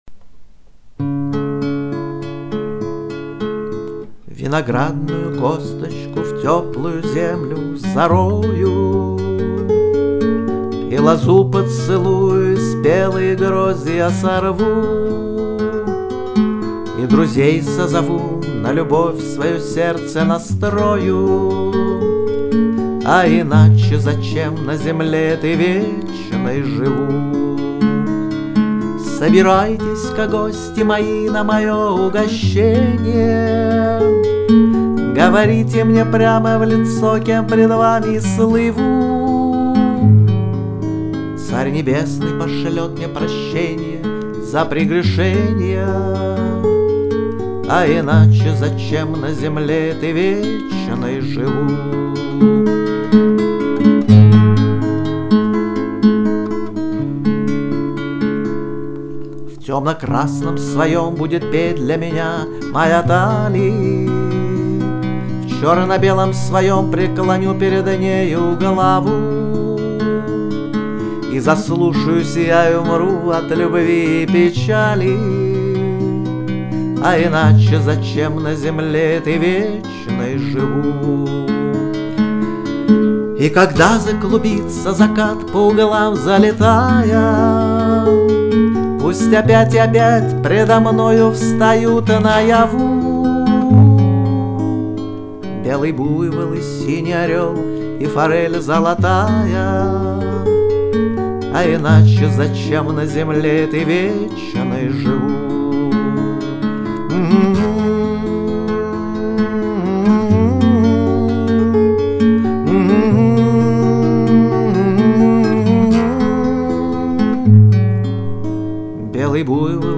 Пение, гитара